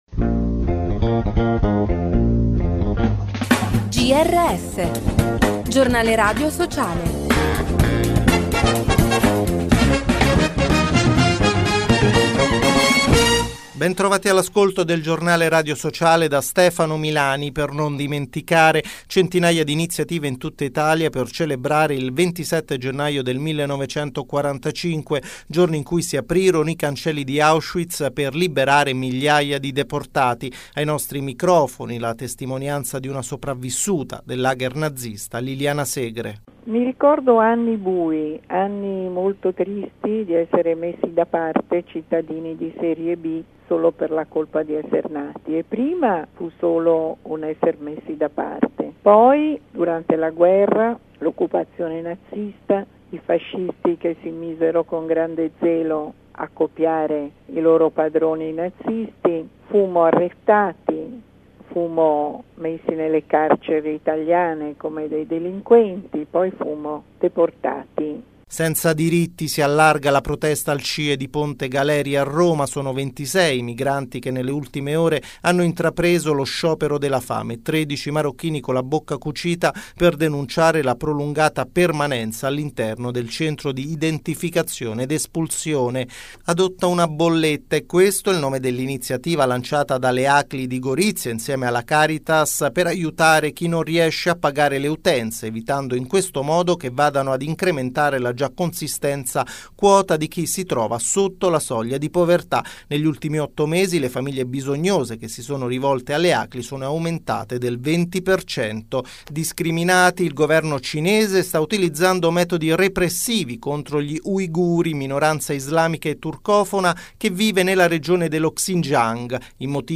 Ai microfoni del Giornale Radio Sociale la testimonianza di una sopravvissuta all’Olocausto